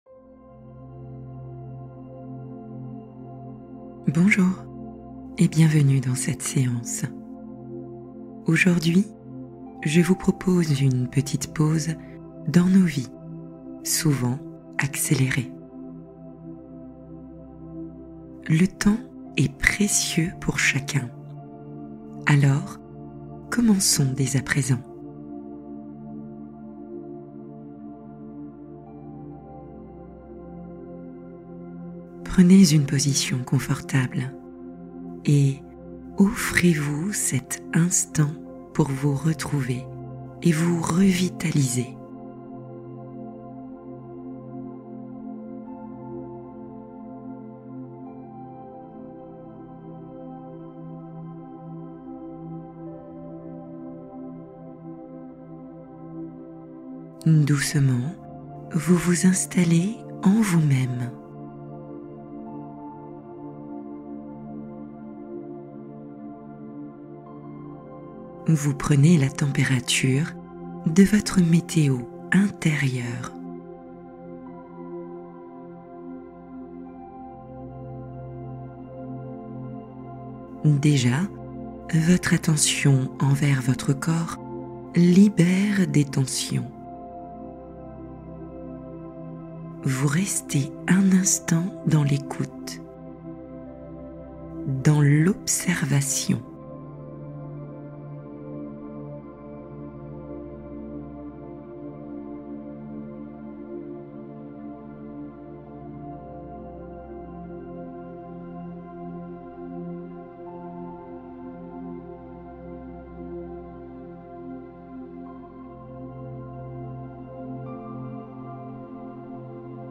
Pardon intérieur : méditation guidée pour se libérer émotionnellement